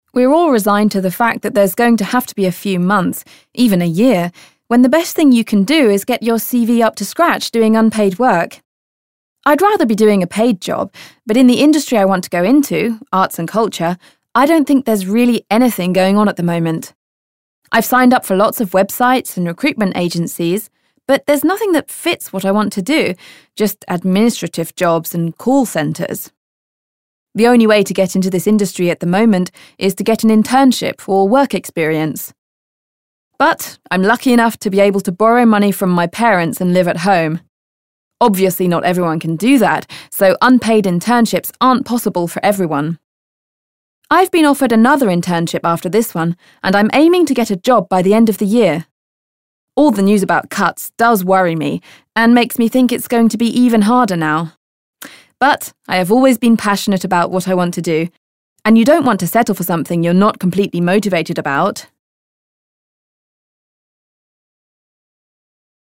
Type : Interview